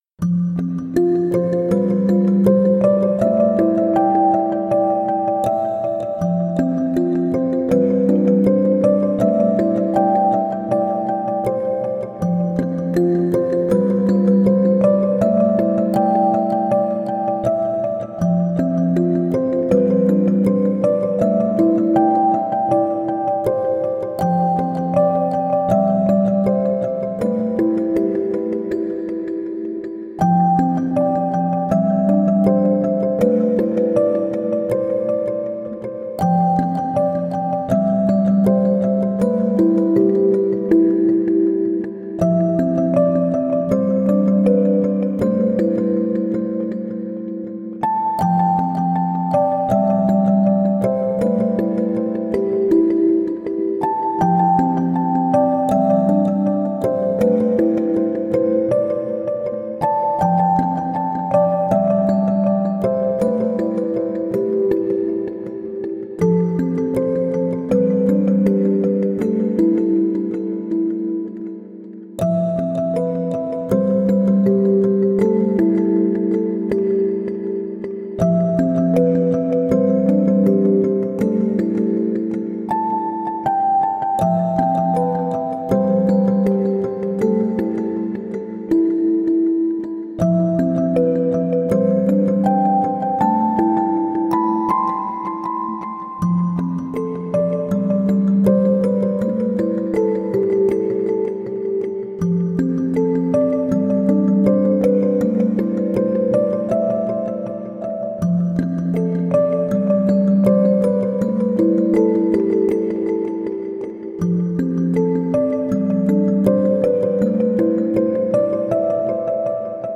静かで穏やかな曲です。【BPM80】